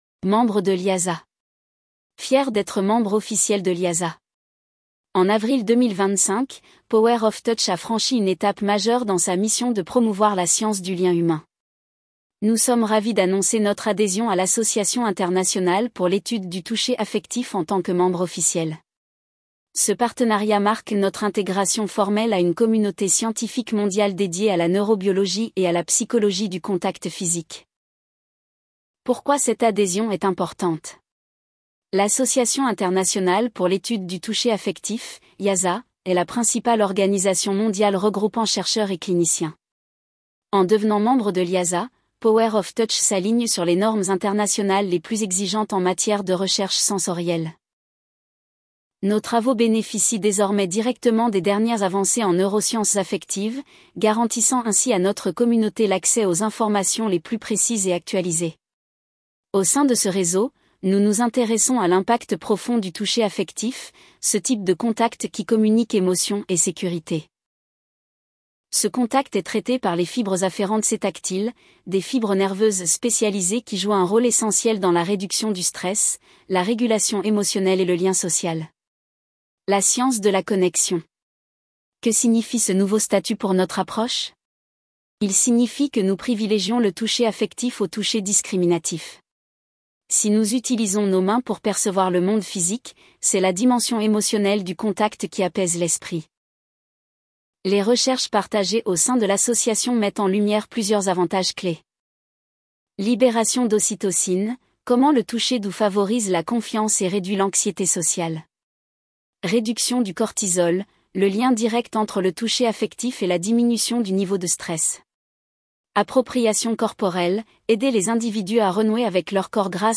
mp3-text-to-voice-membre-de-liasat-power-of-touch.mp3